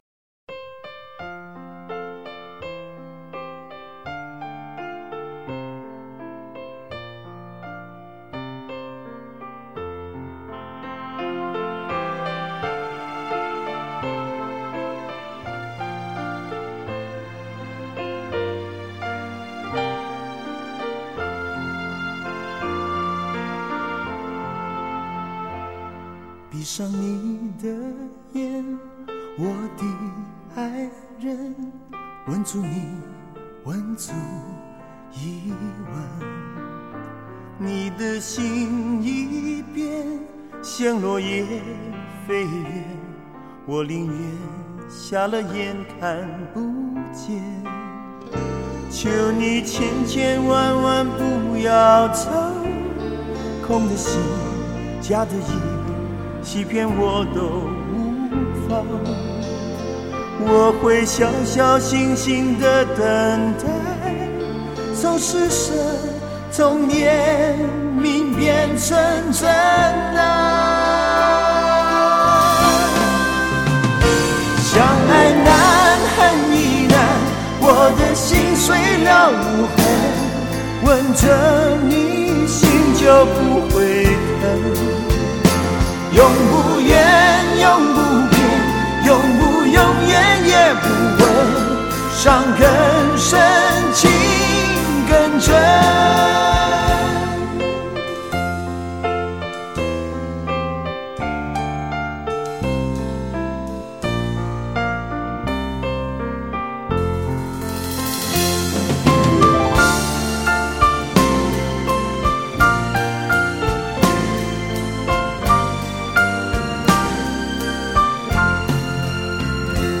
无损音质原人原唱，经典！值得聆听永久珍藏